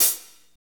Index of /90_sSampleCDs/Northstar - Drumscapes Roland/DRM_Slow Shuffle/KIT_S_S Kit 1 x
HAT S S H0JR.wav